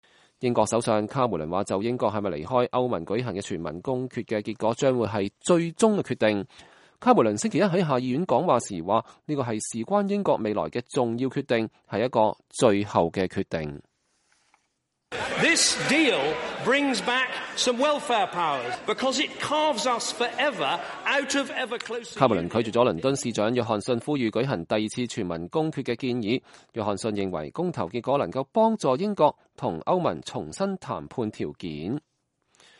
英國首相卡梅倫說，就英國是否離開歐盟舉行的全民公決的結果將會是最終決定。卡梅倫星期一在下議院講話時說，這是事關英國未來的重要決定，是一個最後的決定。